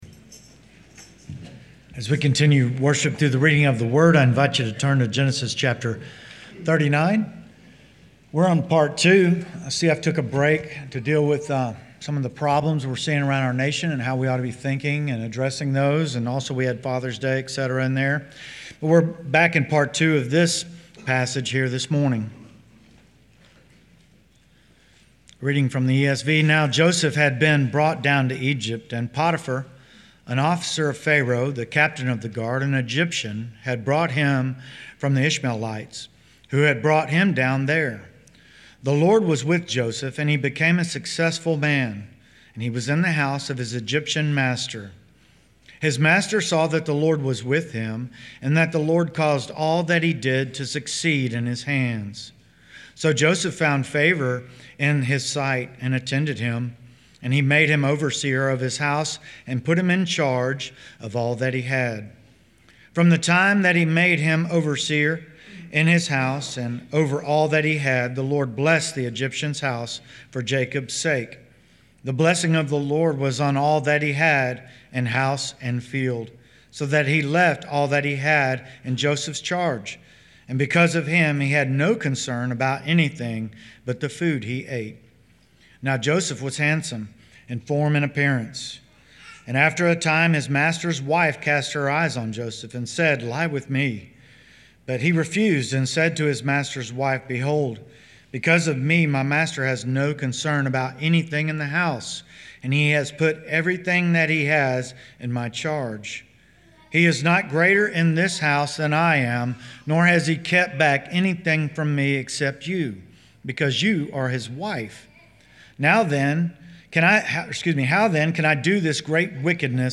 Fellowship of Huntsville Church Sermon Archive